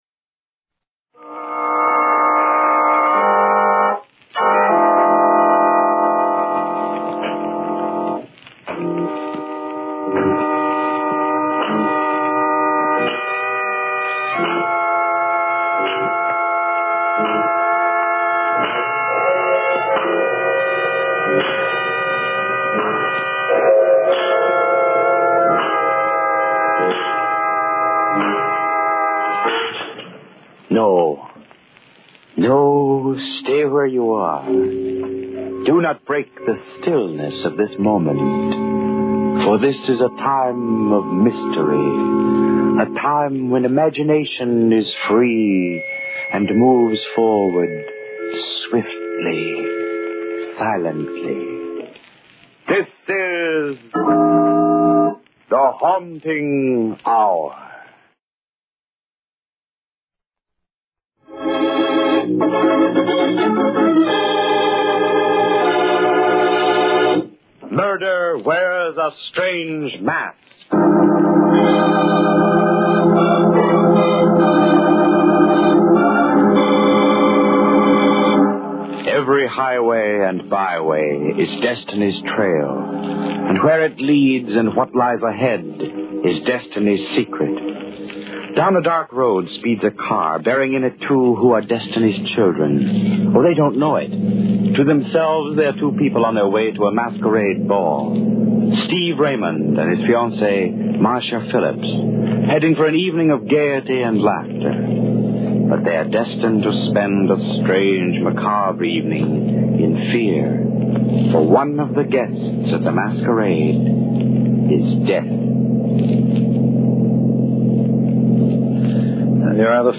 Tags: Radio Horror Mystery Radio Show The Haunting Hour